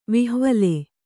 ♪ vihvale